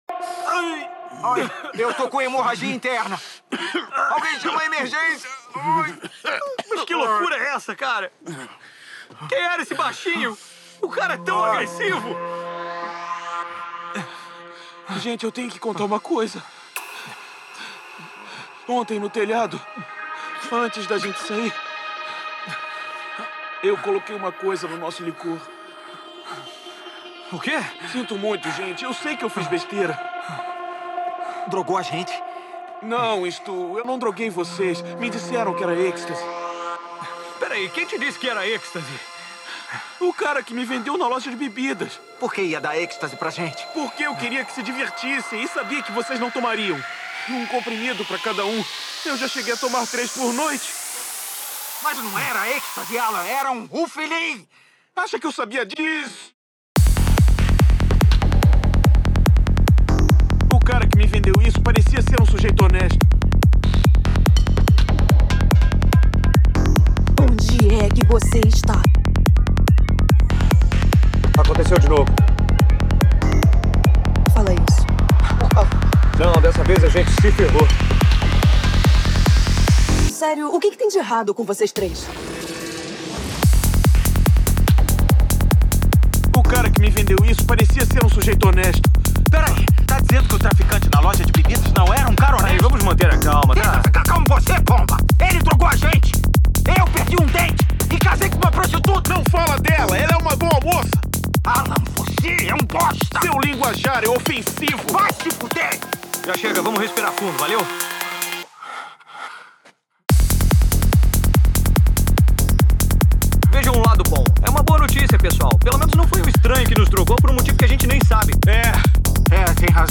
release: 144 BPM